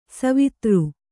♪ savitr